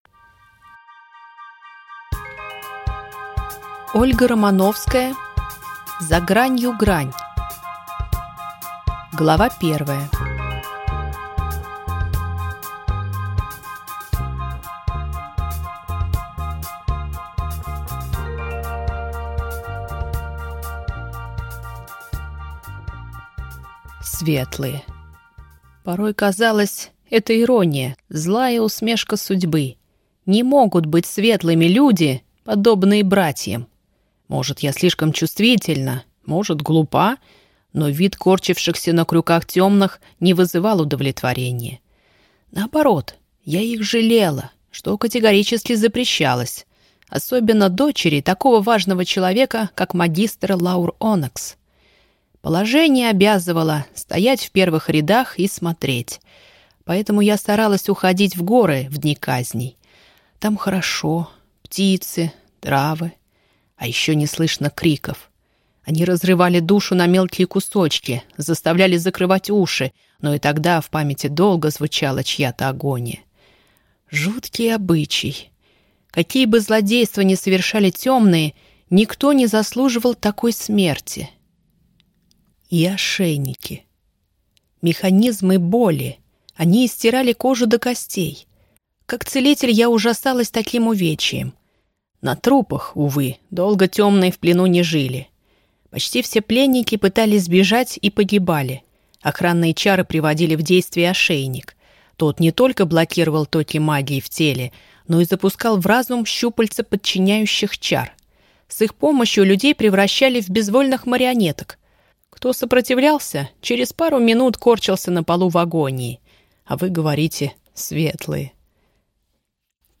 Аудиокнига За гранью грань | Библиотека аудиокниг